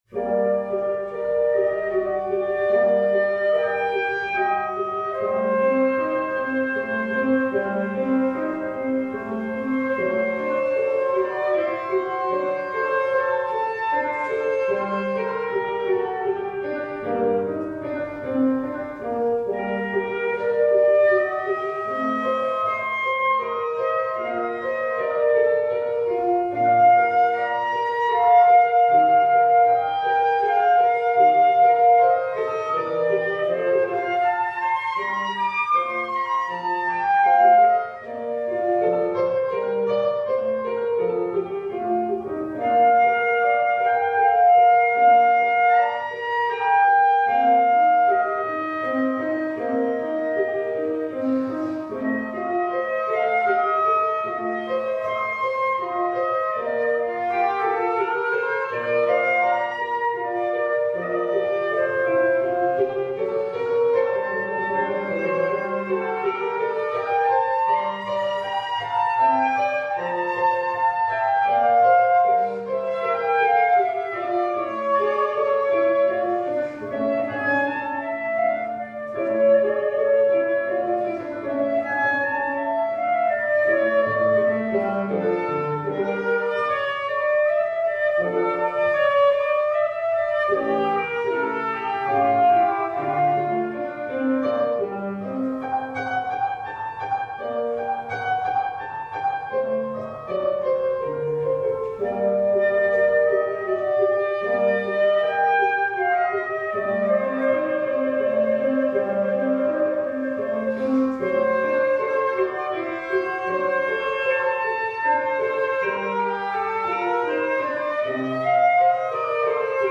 Esz-dúr szonáta